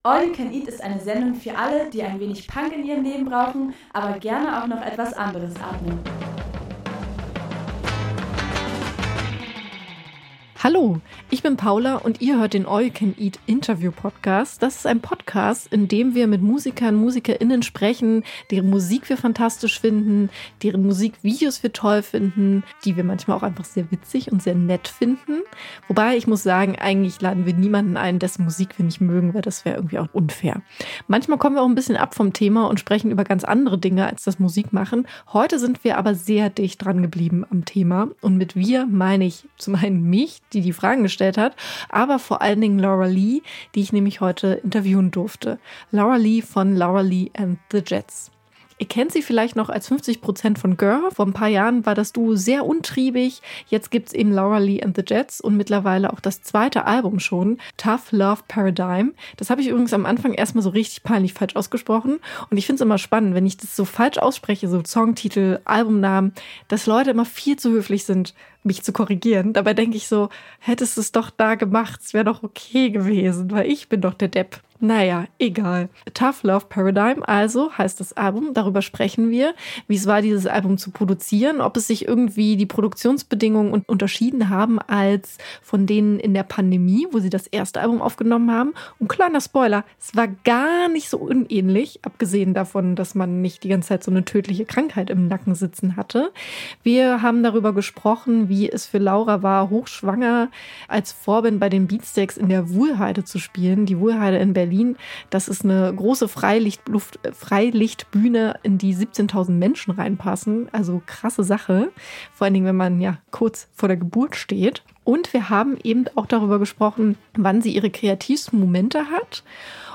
All You Can Eat Interviews